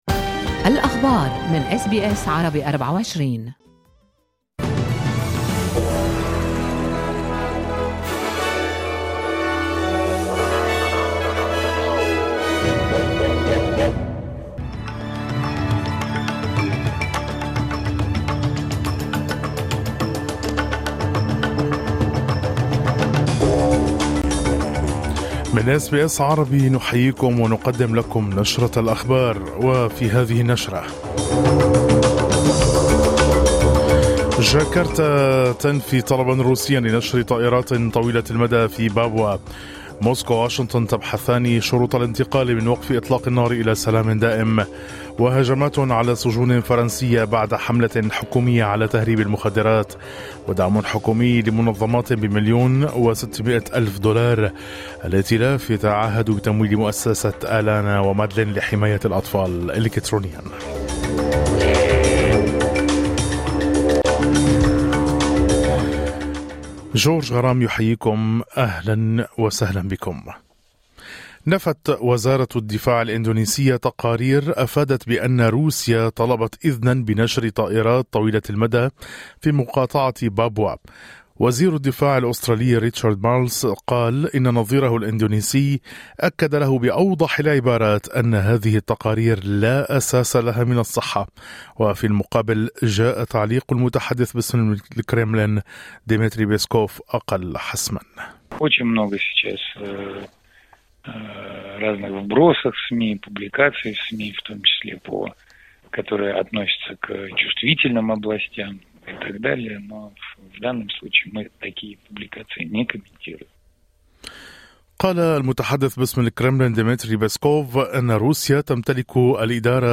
نشرة أخبار الصباح 16/04/2025